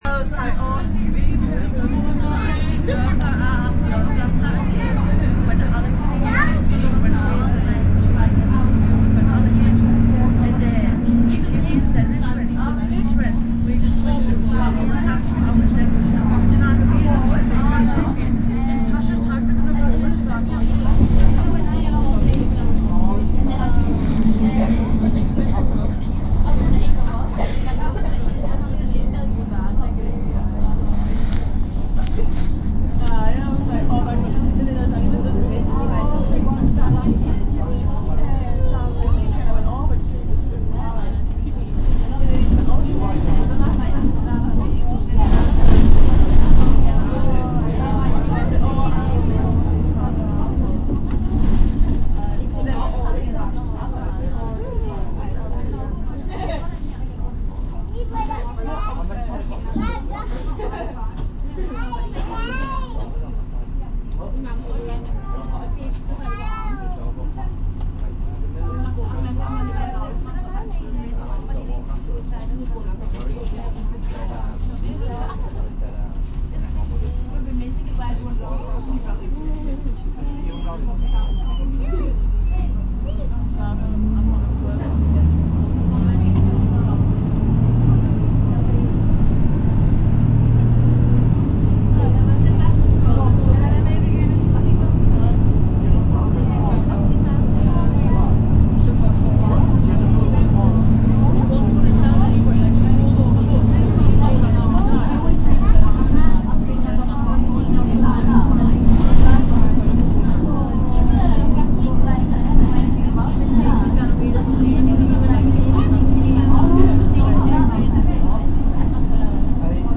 TGIF bus journey